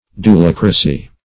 [1913 Webster] The Collaborative International Dictionary of English v.0.48: Dulocracy \Du*loc"ra*cy\, n. See Doulocracy .
dulocracy.mp3